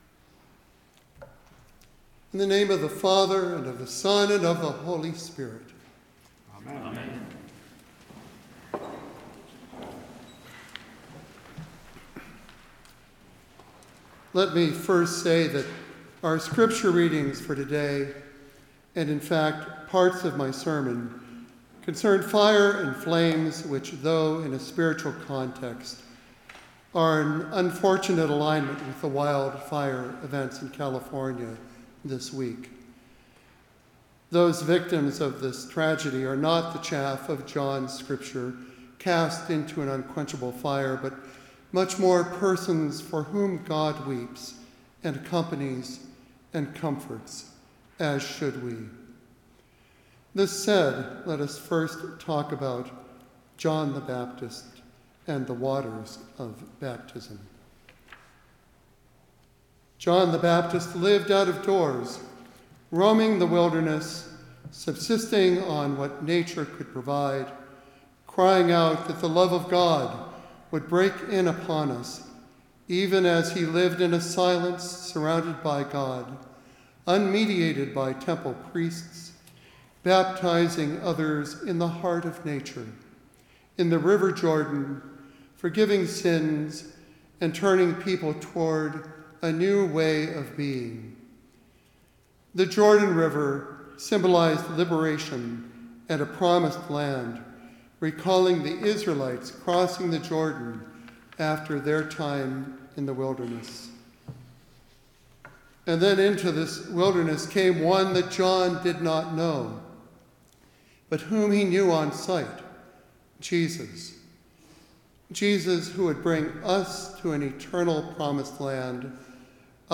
Trinity Episcopal Sermons, Concord, MA